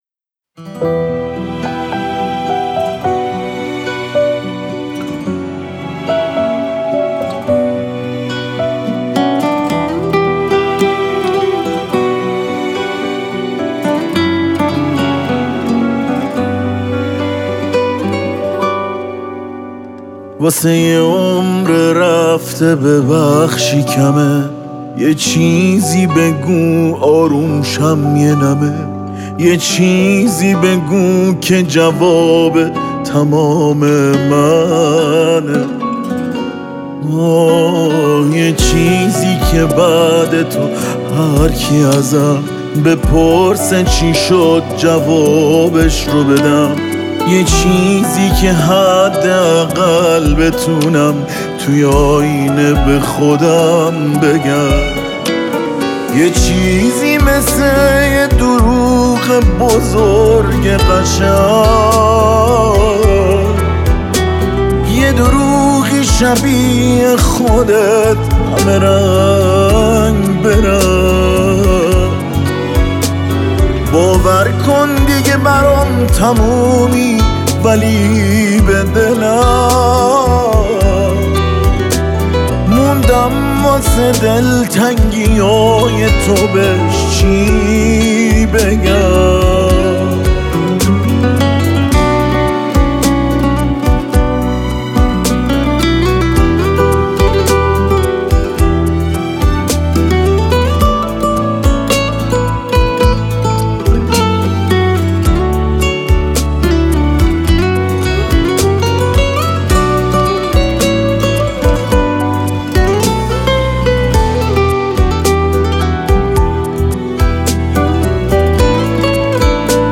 ویولن و بخش‌های زهی